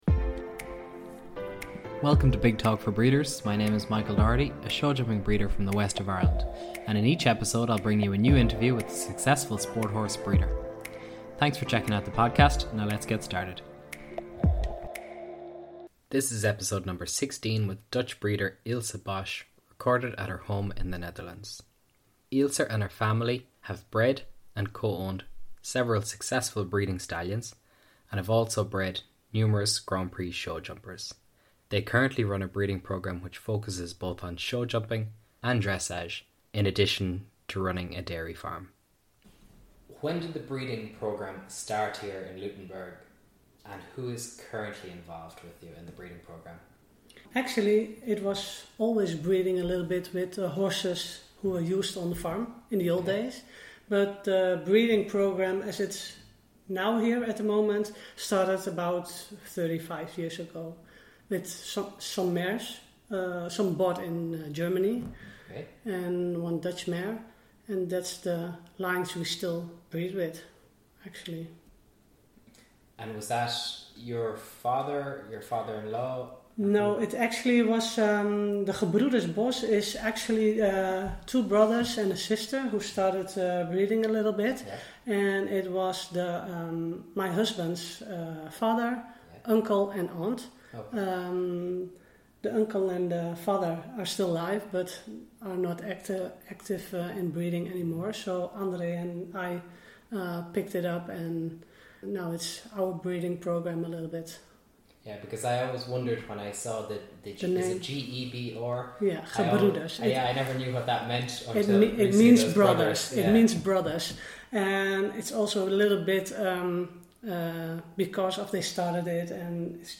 interviewed
and recorded in Luttenberg, The Netherlands.